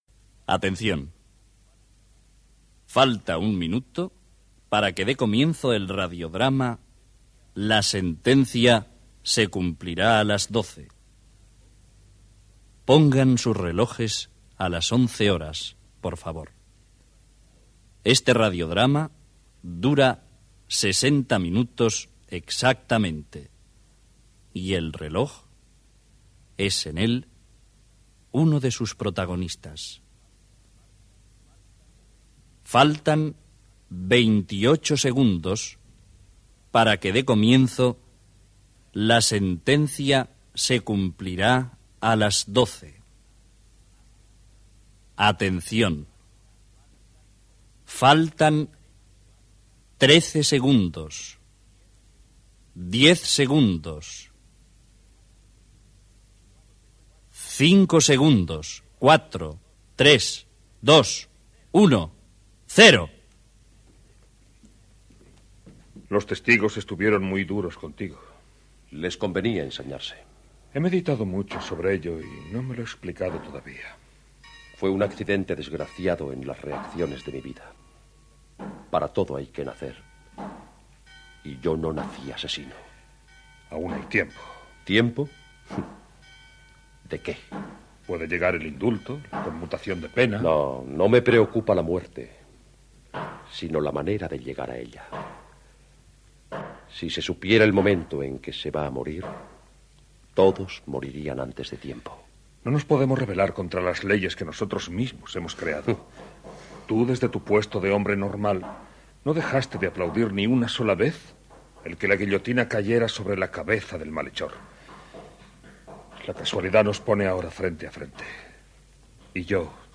"La sentencia se cumplirá a las 12". Avís de posar els rellorges en hora, compte enrere i inici de l'obra en la qual dialoguen el director de la presó i el condemnat a mort
Ficció